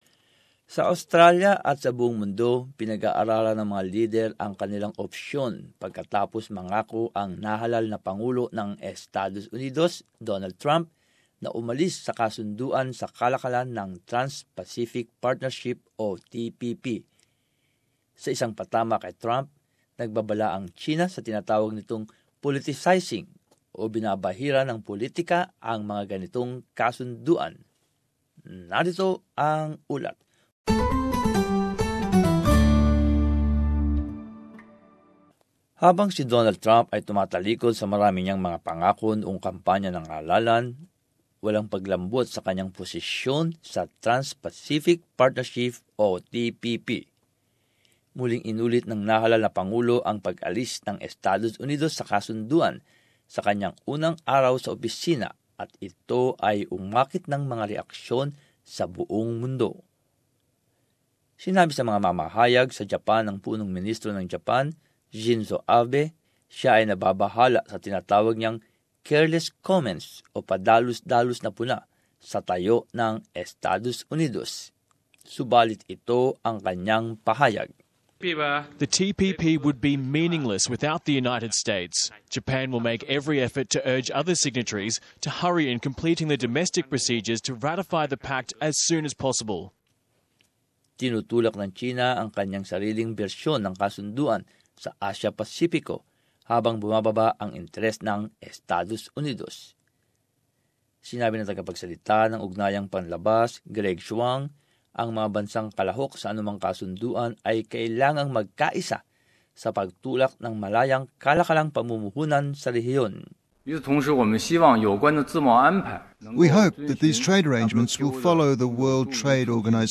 And as this report shows, Japan says the deal will be meaningless without the United States.